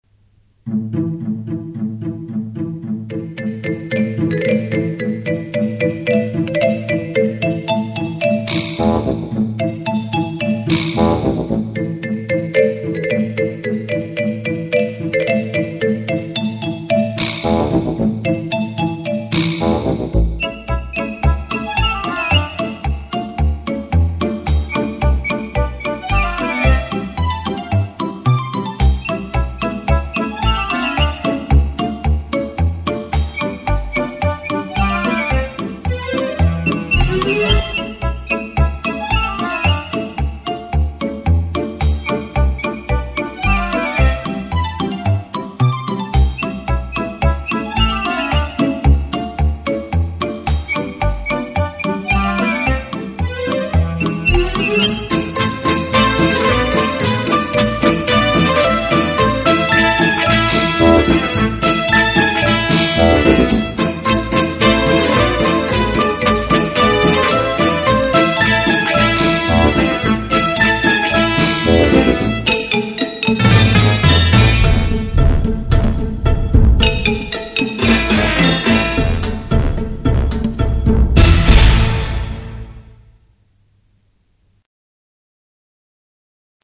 • Качество: 128, Stereo
инструментальные
балет
вальс